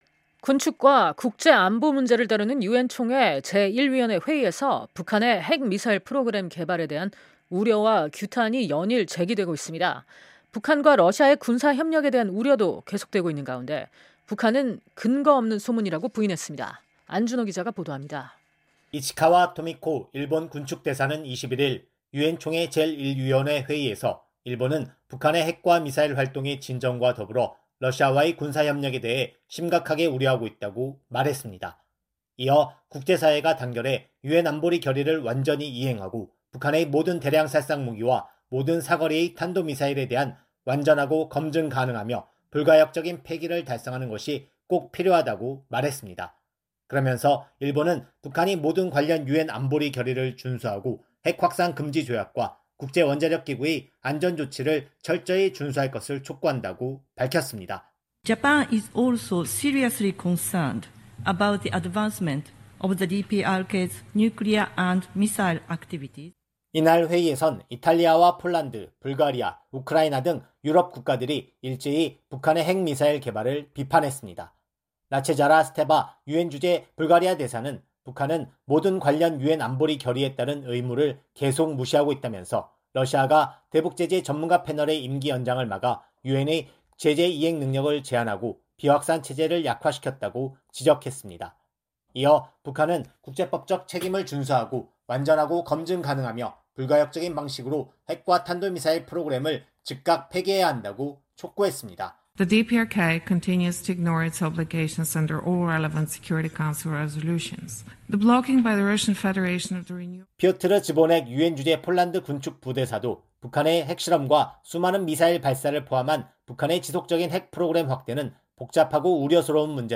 21일 미국 뉴욕 유엔본부에서 개최된 유엔총회 제1위원회 회의. (화면출처: UNTV)